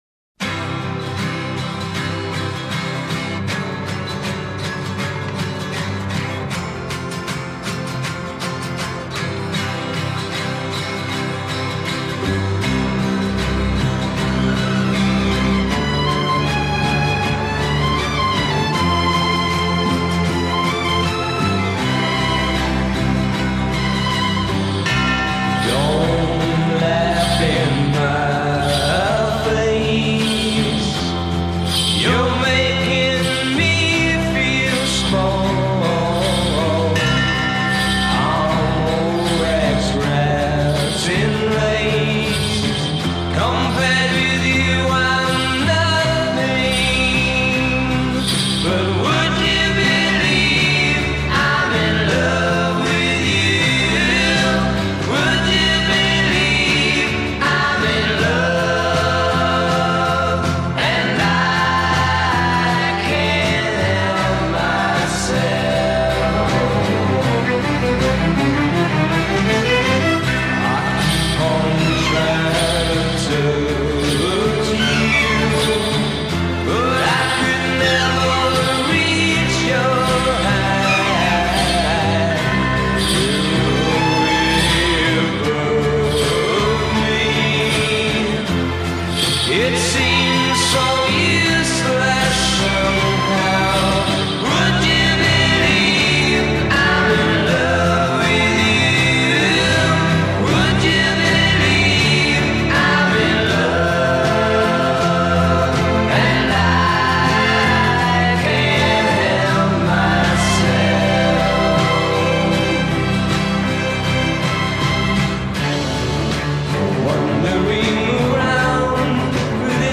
Getting a little more ambitious here.